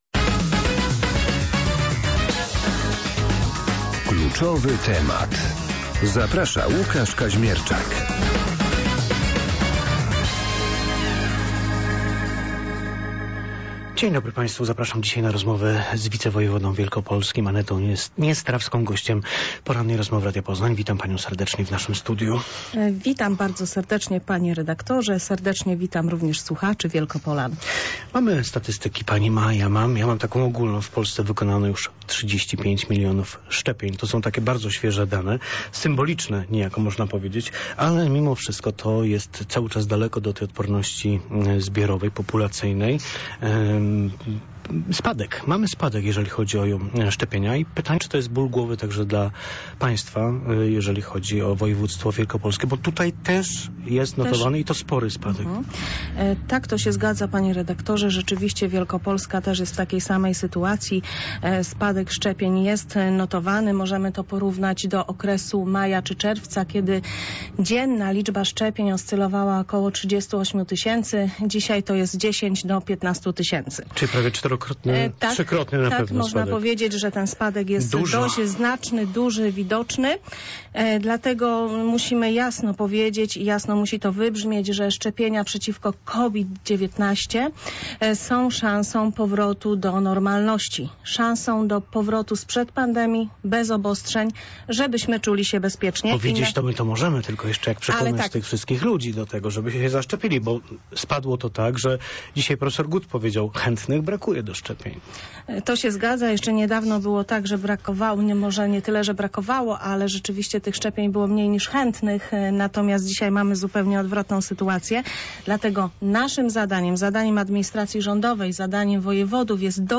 Zainteresowanie szczepieniami jest 3 razy mniejsze niż w maju i czerwcu. O problemie ze spadającym tempem akcji mówiła na antenie Radia Poznań wicewojewoda wielkopolska, Aneta Niestrawska.